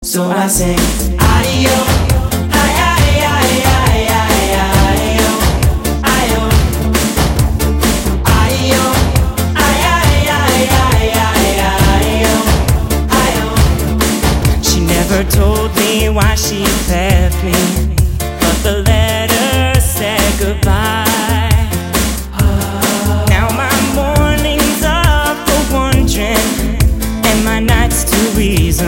Besetzung: Blasorchester
Tonart: F-Dur